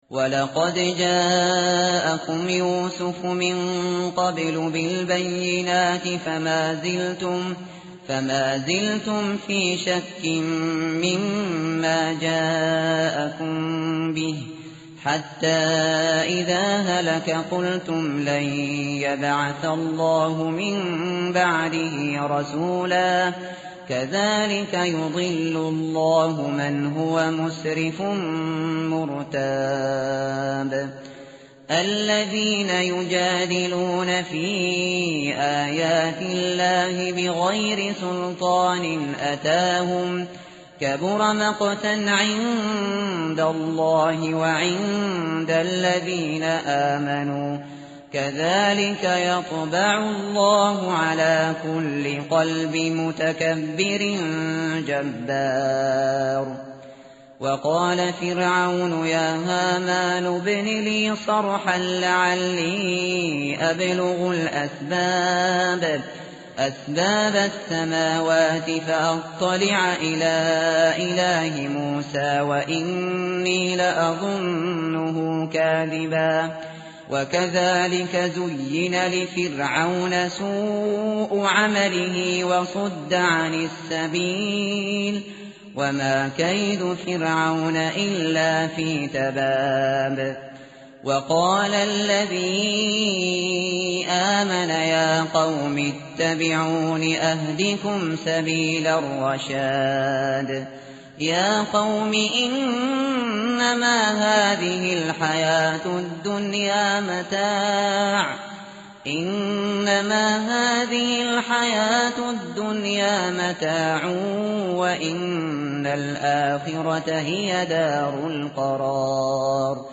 متن قرآن همراه باتلاوت قرآن و ترجمه
tartil_shateri_page_471.mp3